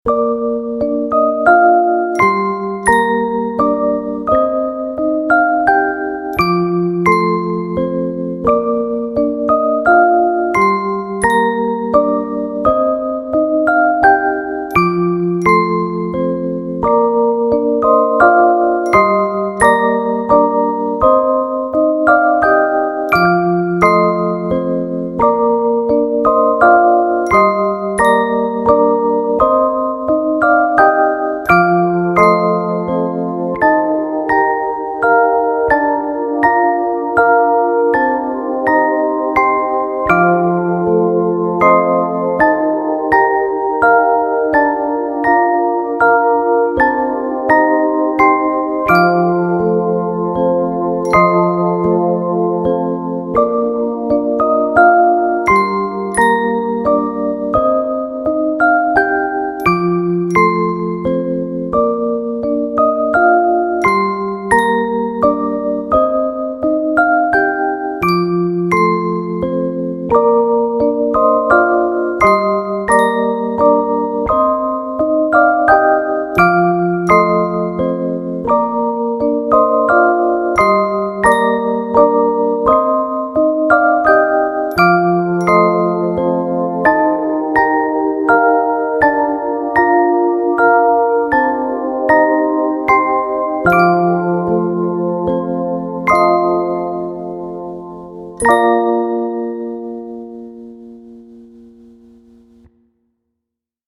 Gentle-Melody.mp3